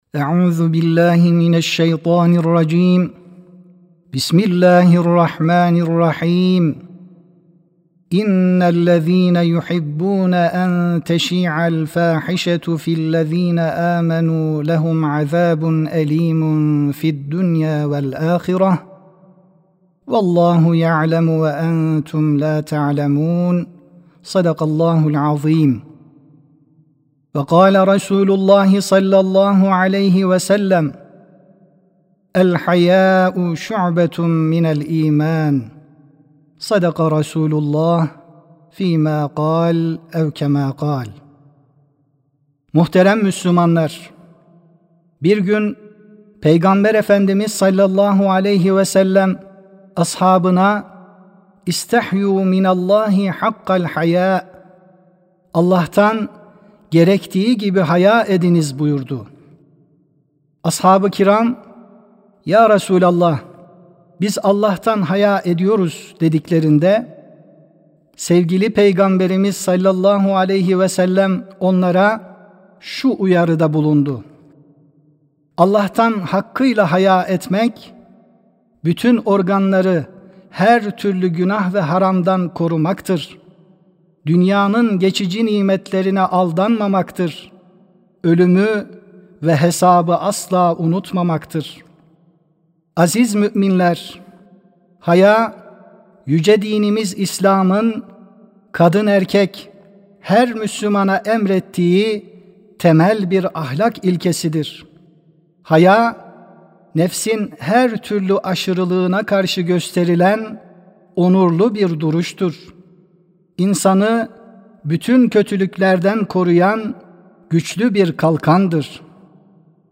Sesli Hutbe (Hayâ, Allah'ın Emri, Fıtratın Gereği).mp3